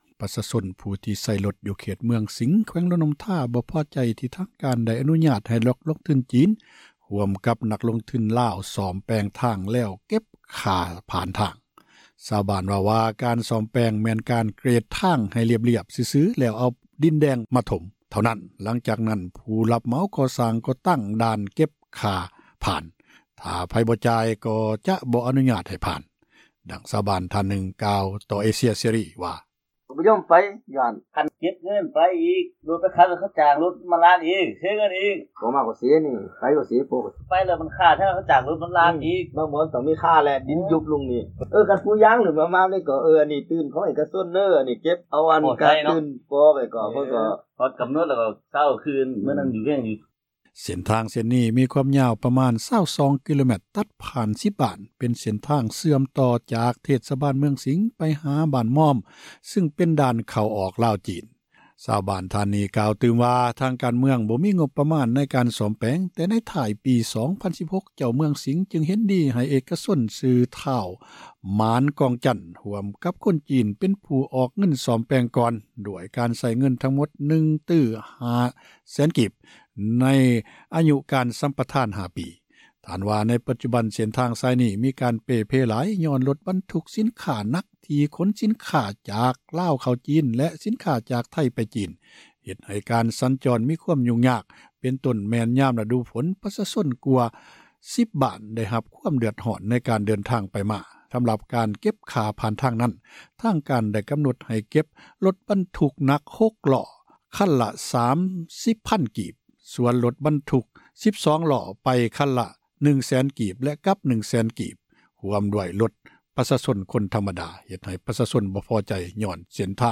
ຊາວບ້ານເວົ້າວ່າ ການສ້ອມແປງ ແມ່ນການ ເກຣດທາງໃຫ້ລຽບ ແລ້ວປູດ້ວຍດິນແດງ ເທົ່ານັ້ນ, ຈາກນັ້ນ ຜູ້ຮັບເໝົາ ກໍຕັ້ງດ່ານ ເກັບເງິນ ຖ້າໃຜບໍ່ຈ່າຍ ຈະບໍ່ ອະຍຸຍາດ ໃຫ້ຜ່ານ, ດັ່ງຊາວບ້ານ ທ່ານນຶ່ງ ກ່າວຕໍ່ ເອເຊັຽເສຣີ ເປັນສຳນຽງພາສາ ລື້ວ່າ: